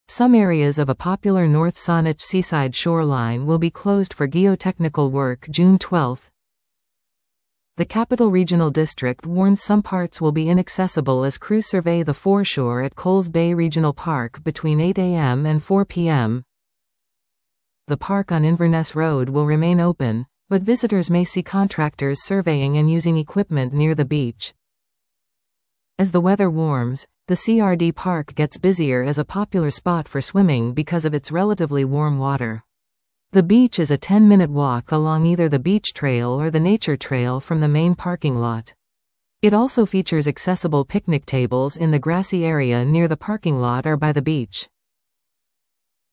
Capital Regional District Listen to this article 00:00:46 Some areas of a popular North Saanich seaside shoreline will be closed for geotechnical work June 12.